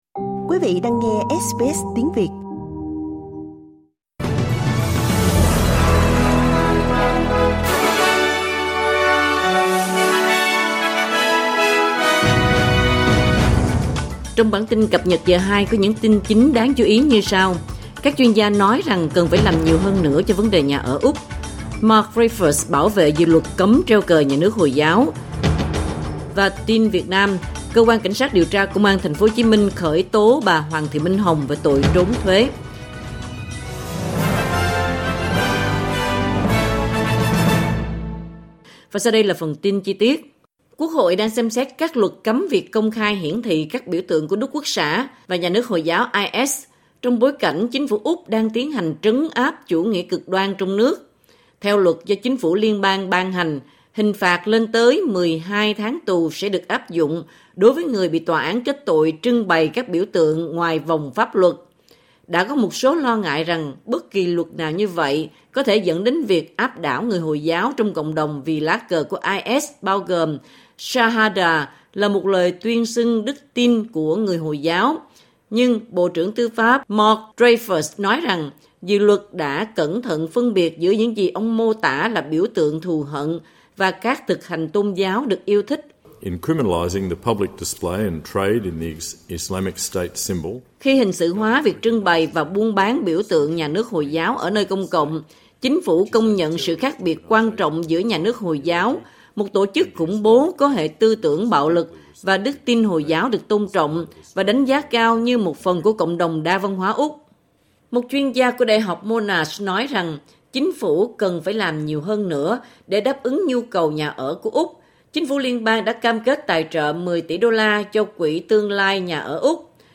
Vietnamese news bulletin Source: Getty